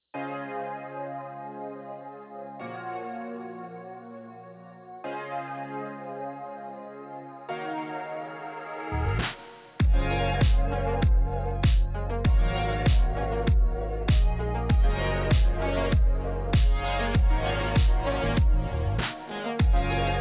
Music-On-Hold Options